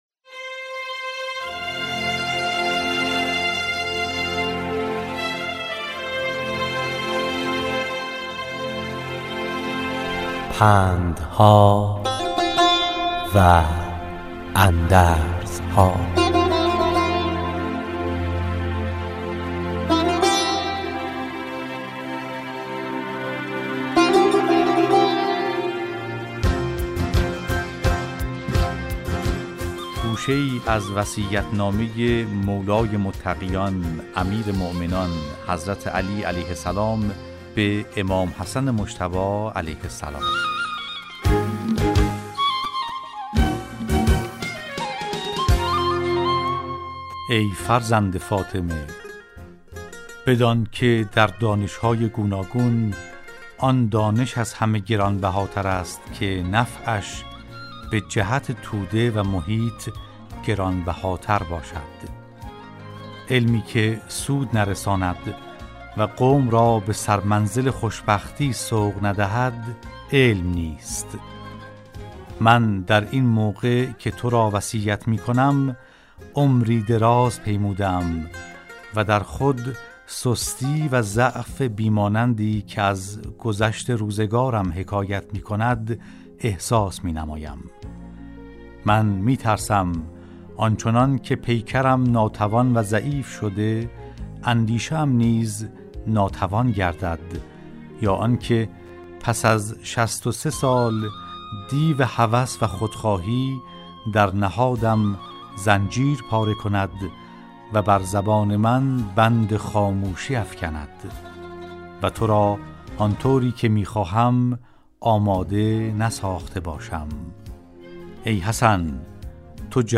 در برنامه " پندها و اندرزها"، راوی برای شنوندگان عزیز صدای خراسان، حکایت های پندآموزی را روایت می کند .